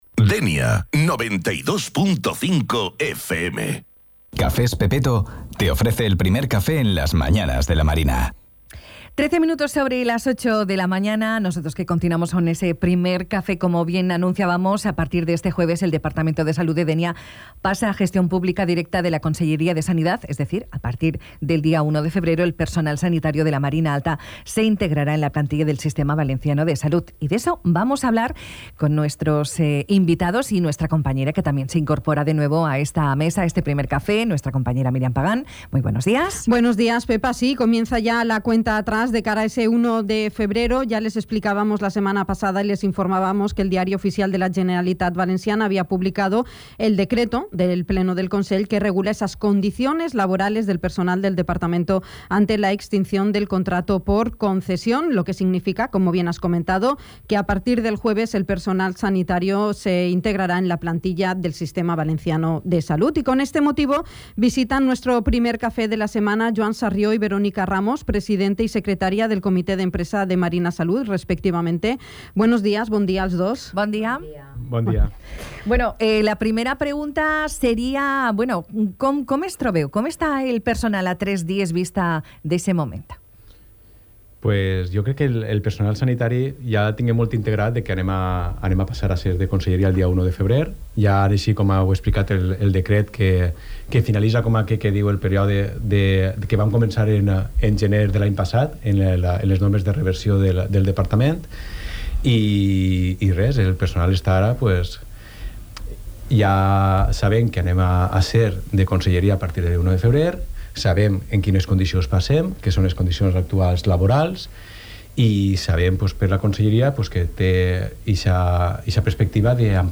Entrevista-Comite-Empresa-Marina-Salud.mp3